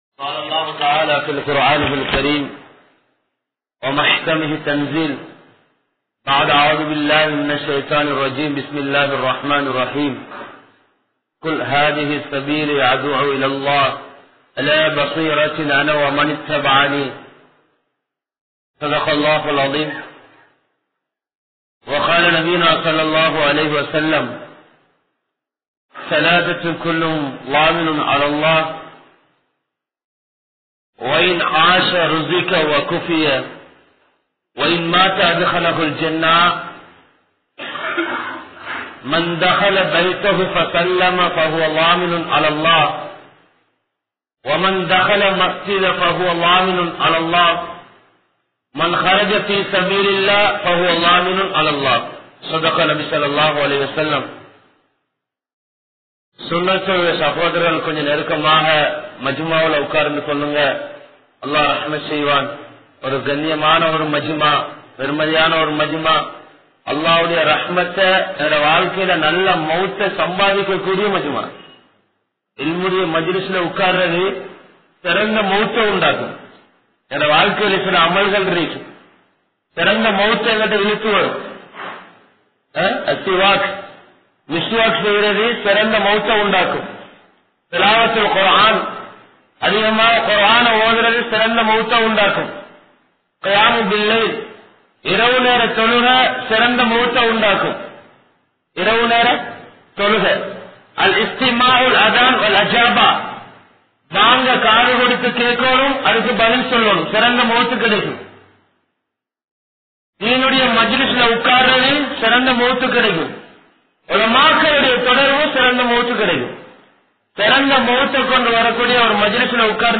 Deenudaiya Unmaiyaana Kavalai(தீனுடைய உண்மையான கவலை) | Audio Bayans | All Ceylon Muslim Youth Community | Addalaichenai